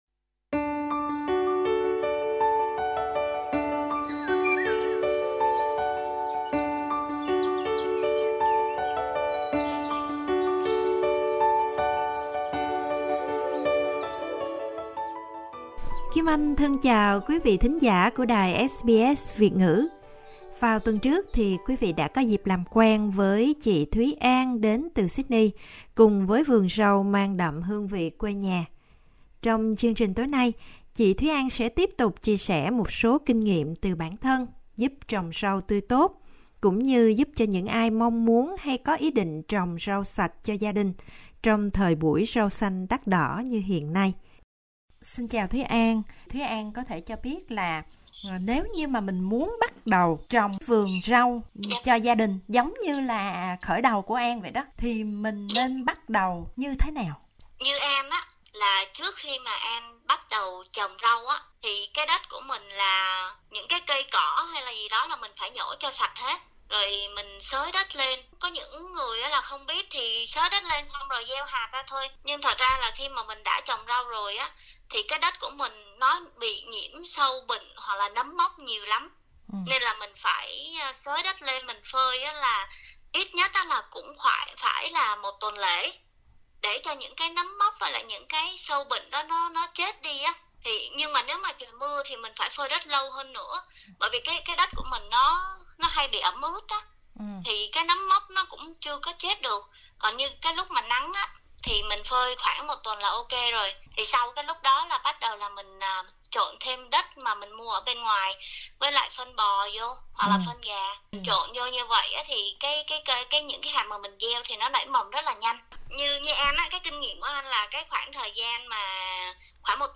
Mời quý vị nhấn vào audio để nghe phỏng vấn và có thêm thông tin chi tiết về cách trồng rau cho gia đình.